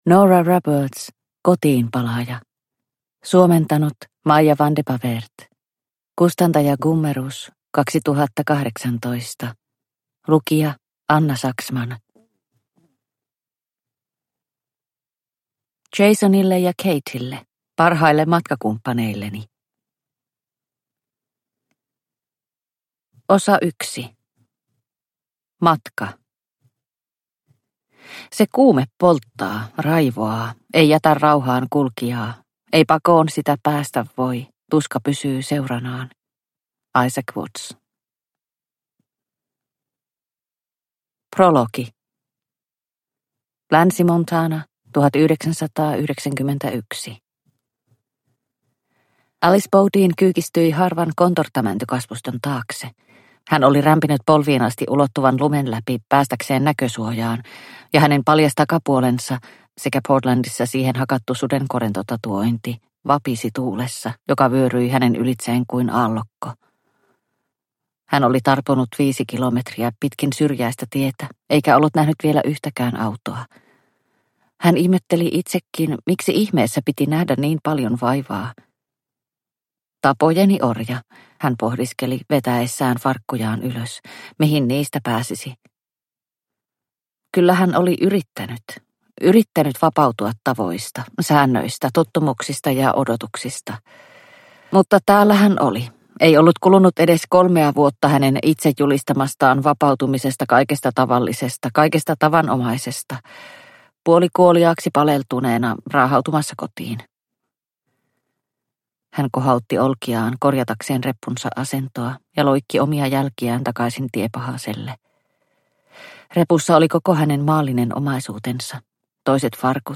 Kotiinpalaaja – Ljudbok – Laddas ner